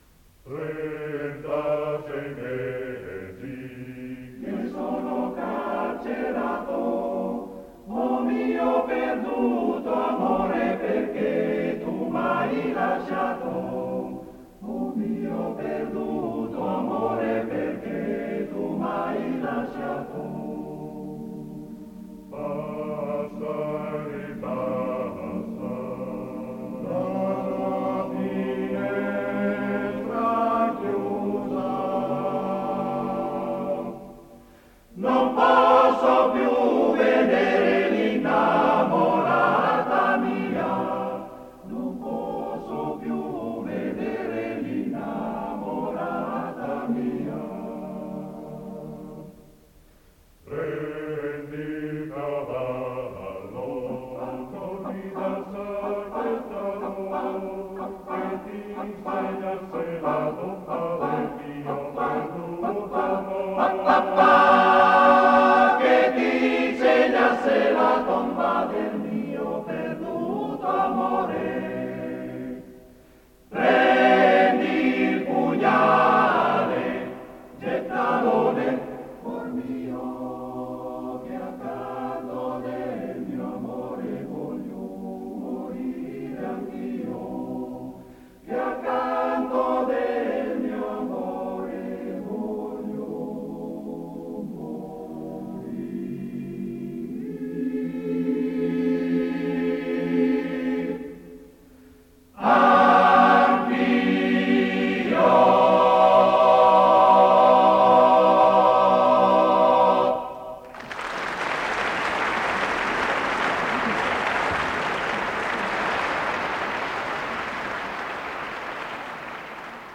Esecutore: Coro Edelweiss
RAI Auditorium 18/6/83